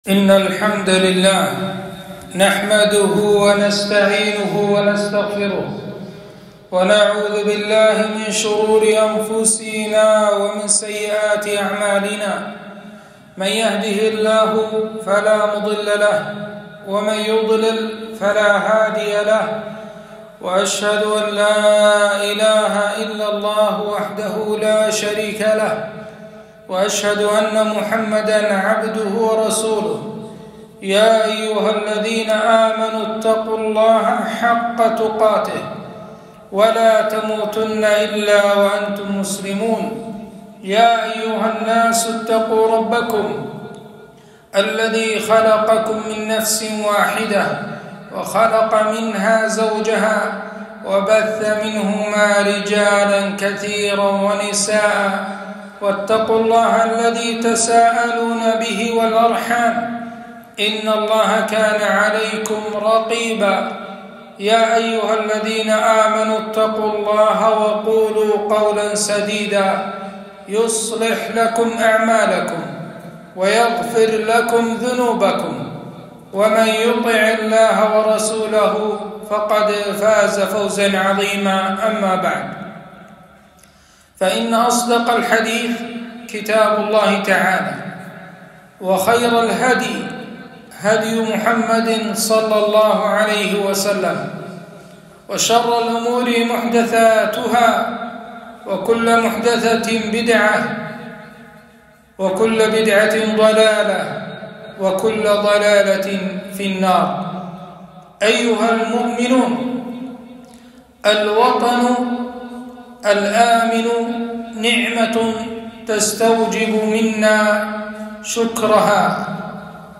خطبة - الأمن في الأوطان